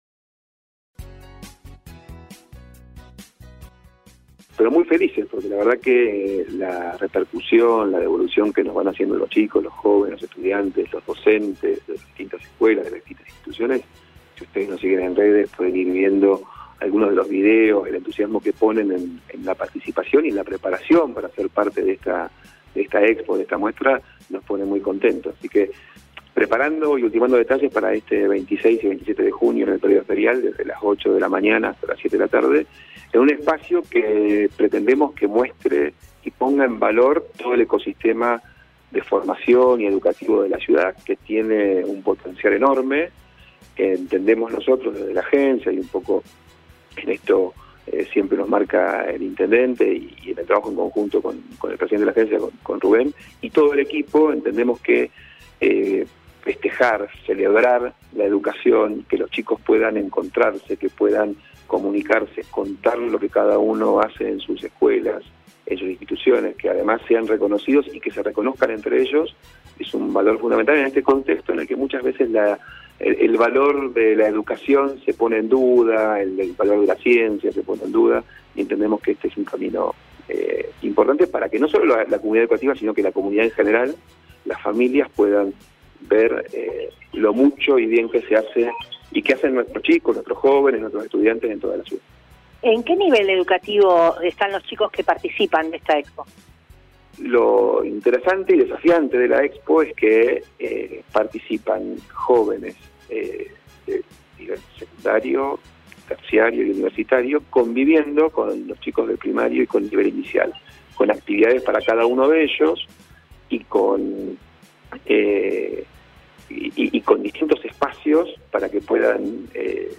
hizo la invitación correspondiente en el aire de LA MAÑANA DE HOY: